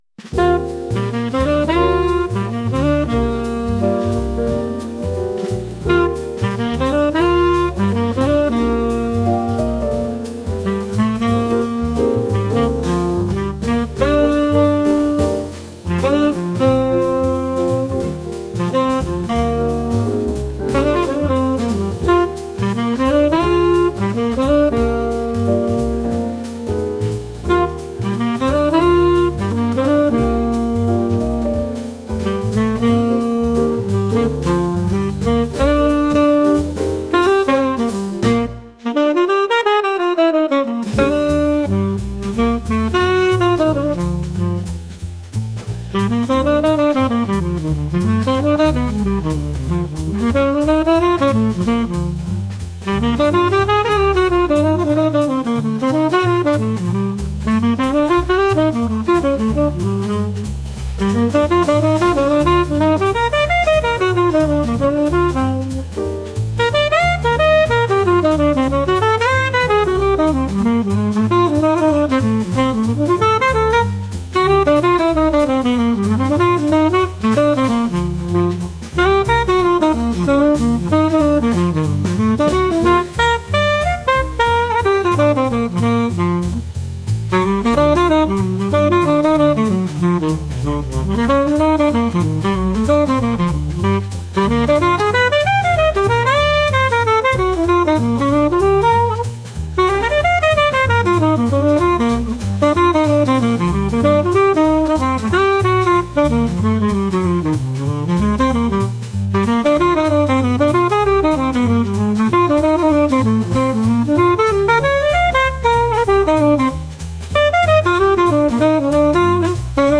ピアノメインのシカゴジャズ曲です。